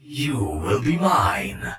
OC Puppeteer Voice Over